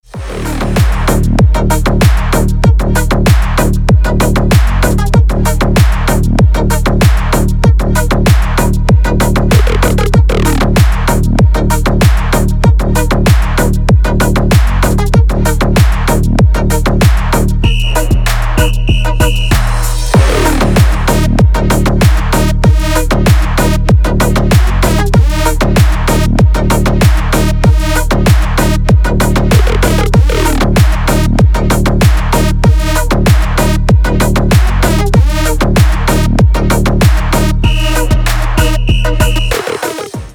Dub Mix